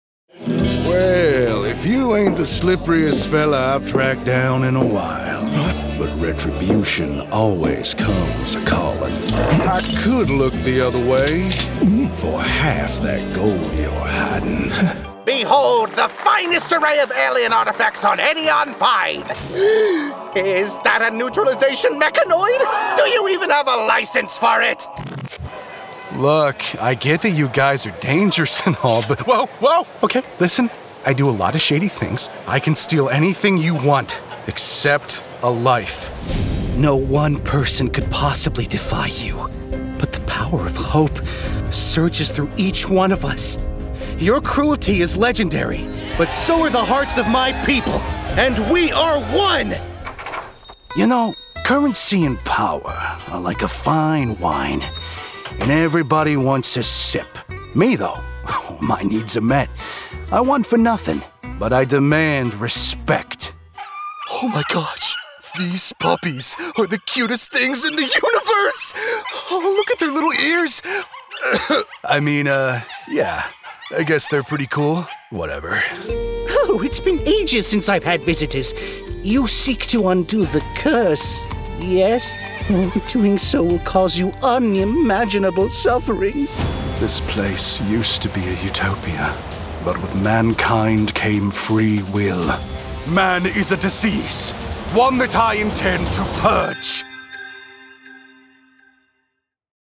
Young Adult, Adult, Mature Adult
Has Own Studio
british rp | character
new york | character
texan us | natural
ANIMATION 🎬
creatures
husky